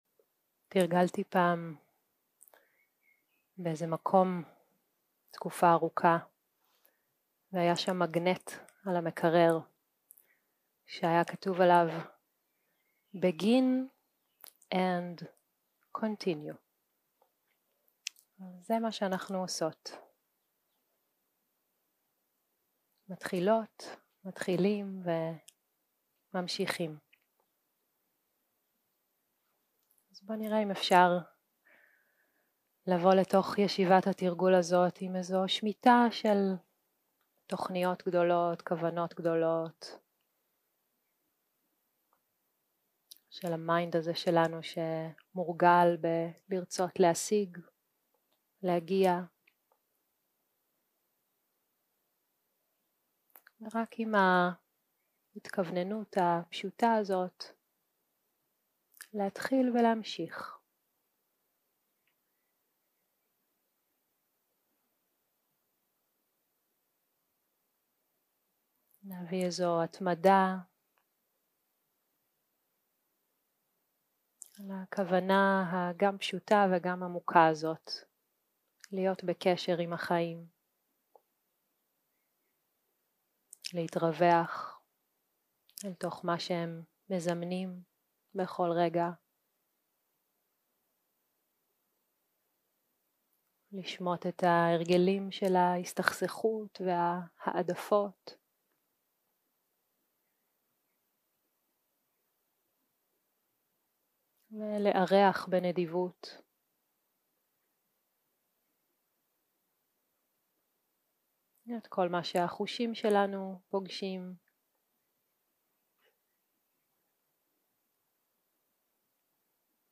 יום 3 - הקלטה 6 - צהרים - מדיטציה מונחית
Dharma type: Guided meditation